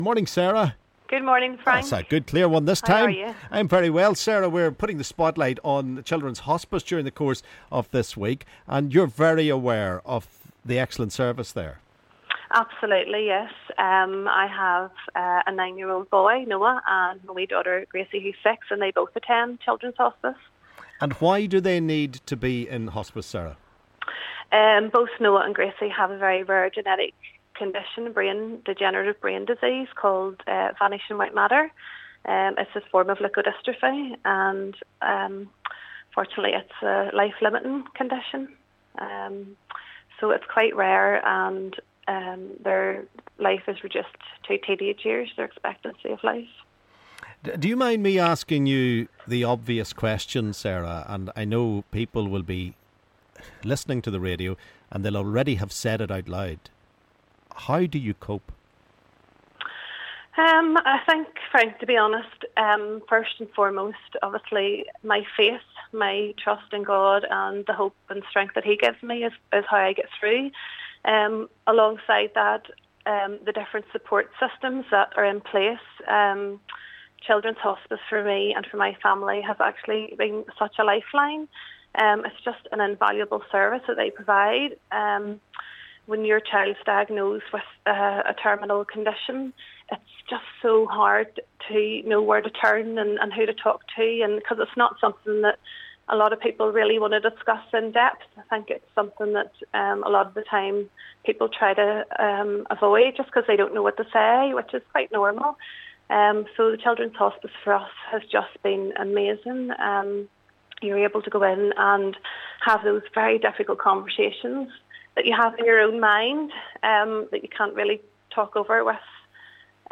LISTEN: Mum of two tells us how important the NI Children's Hospice is to her family